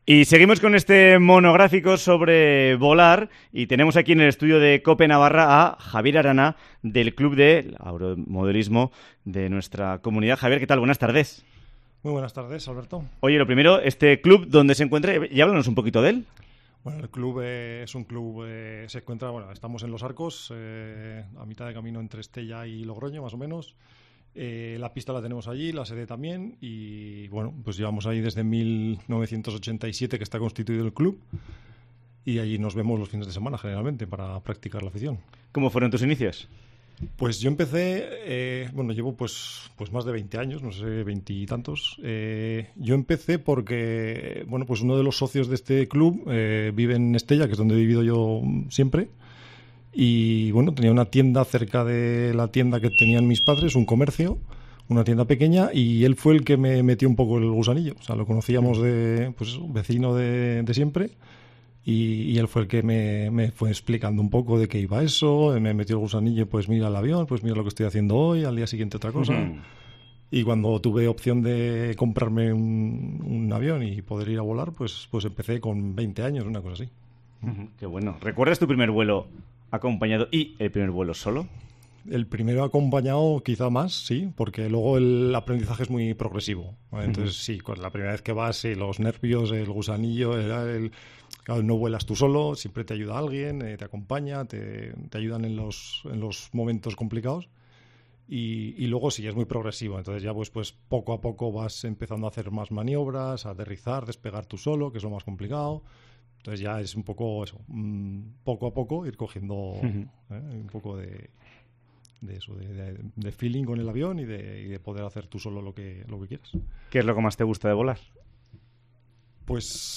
Semana dedicada en COPE Navarra a "volar". Entrevista